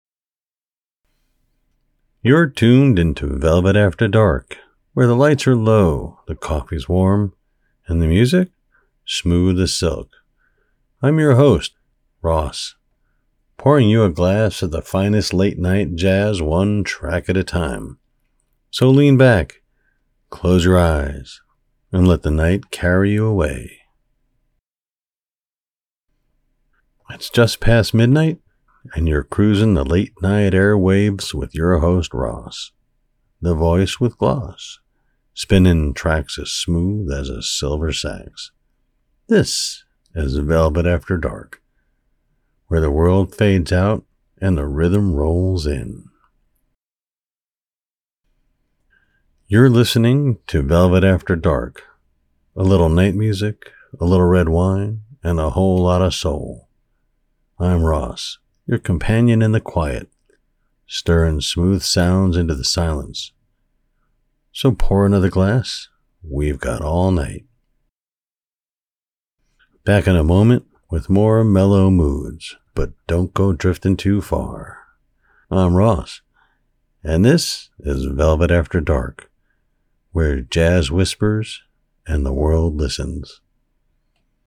Late night jazz radio DJ
Senior